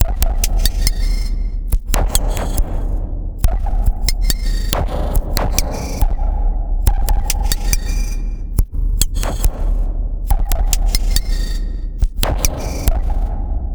Abstract Rhythm 18.wav